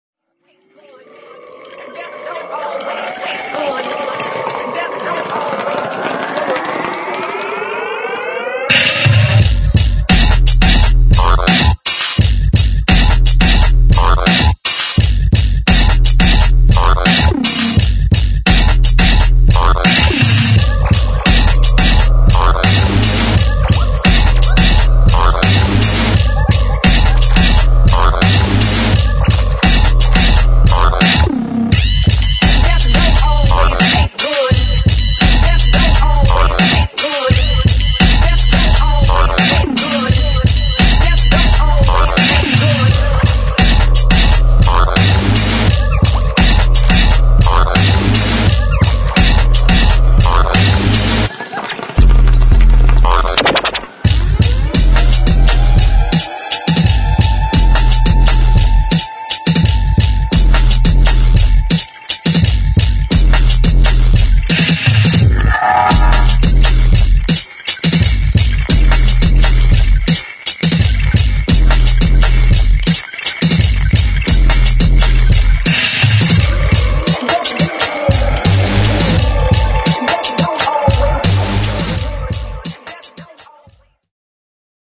[ BASS / FUTURE BEATS / ABSTRACT / EXPERIMENTAL ]